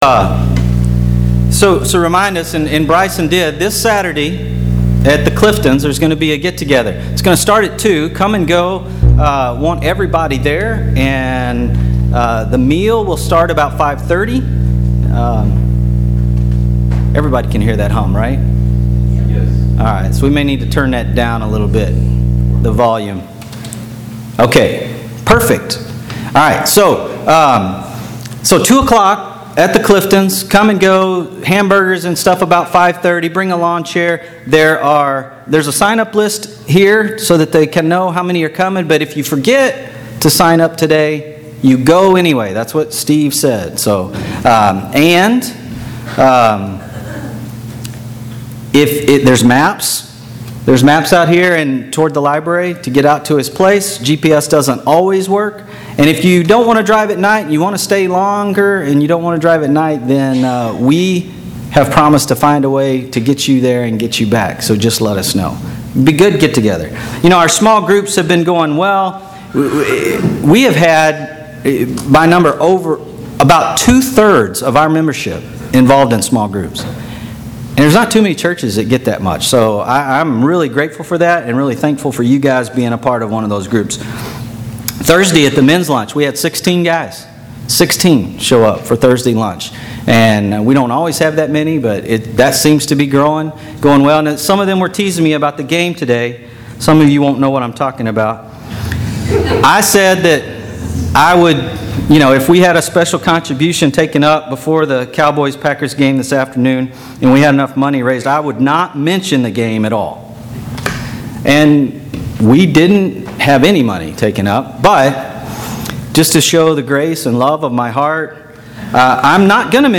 Sermons - Clifton Church of Christ